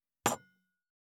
227,食器,グラス,コップ,工具,小物,雑貨,コトン,トン,ゴト,ポン,
コップ効果音物を置く